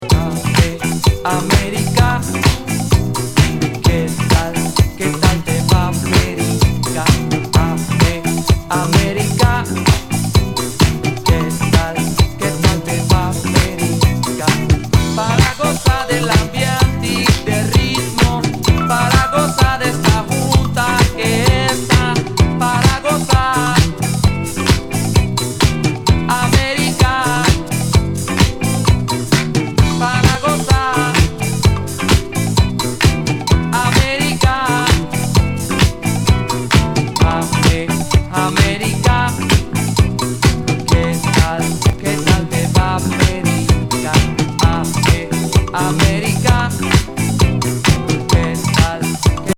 ベルギーNWディスコ・グループの、トロピカル・グルーヴィ・ディスコ♪UK PRESS.